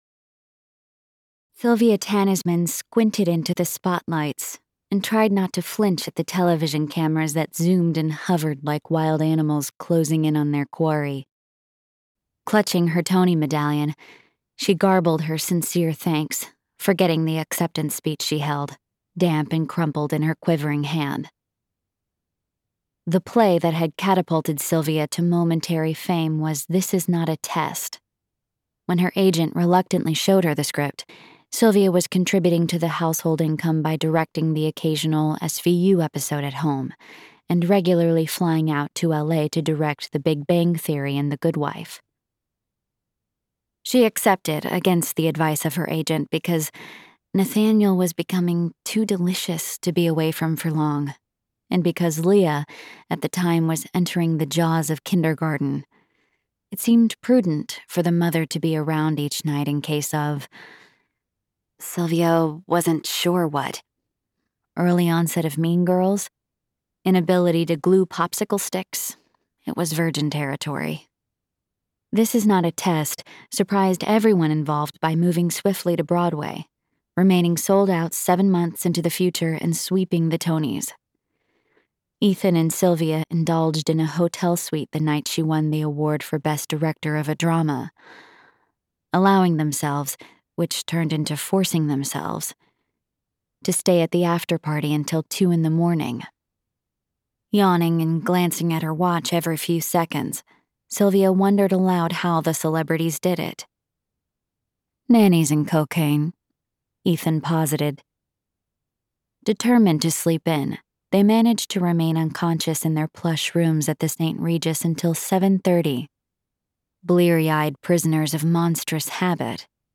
• Audiobook • 11 hrs, 3 mins